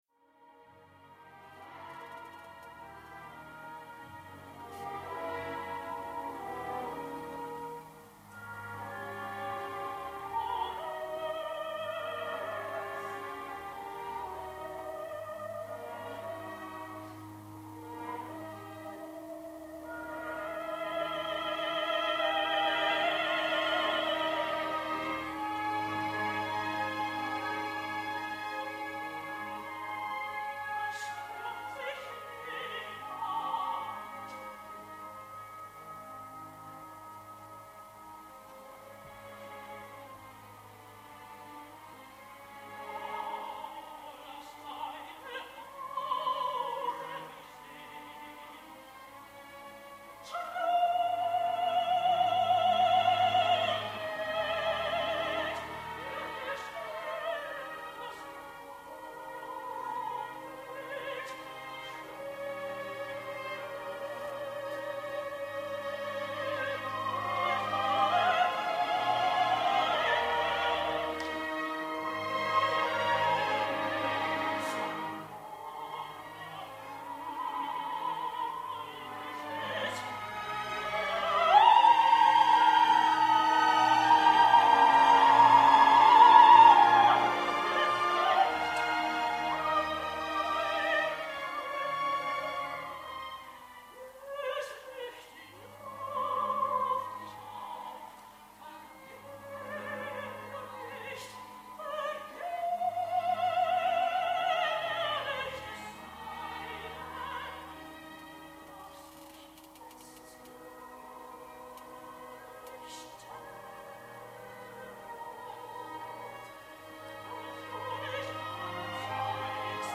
Dramatischer Sopran
Diese Hörproben sind Live-Mitschnitte durch Bühnenmikrophone, stellen also keine Studioqualität dar und sollen lediglich einen Stimm- und Interpretationseindruck vermitteln.